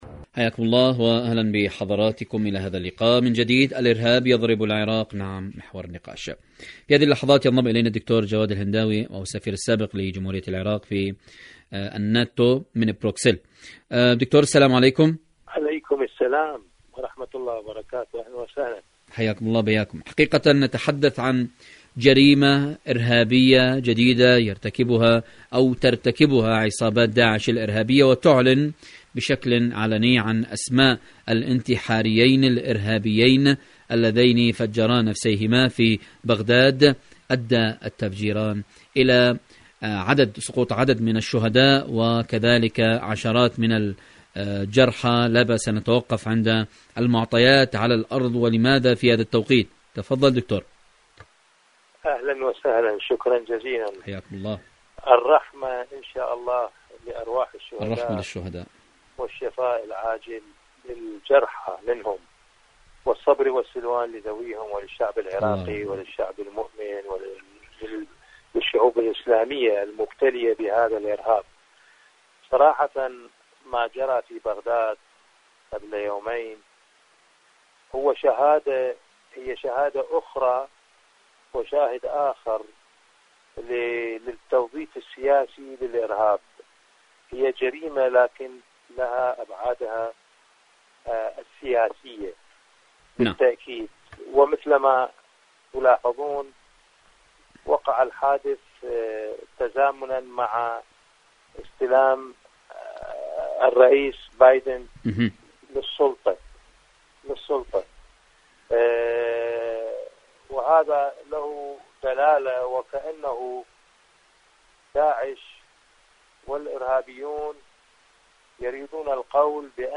إذاعة طهران-ألو طهران: مقابلة إذاعية مع جواد هنداوي السفير السابق لجمهورية العراق في الناتو من بروكسل حول موضوع من جديد الإرهاب يضرب العراق.